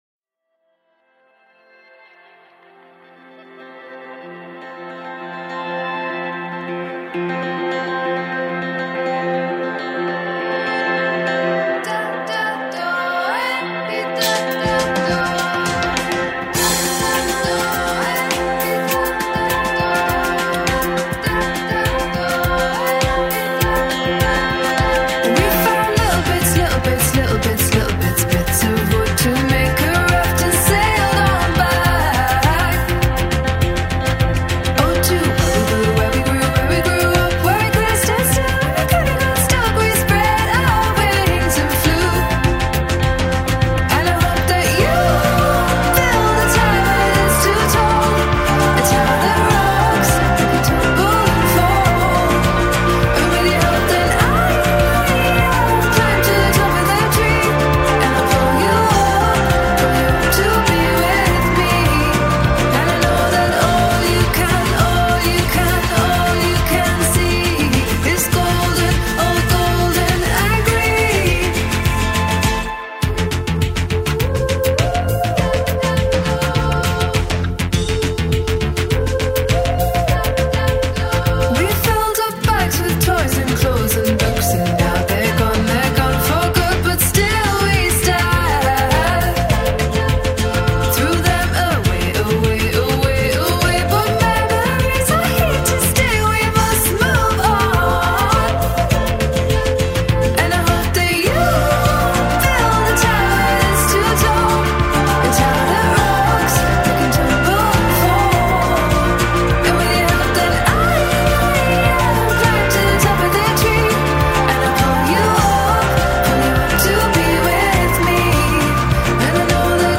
London band
synthpop
synth-driven shout-out for better times, pure and simple.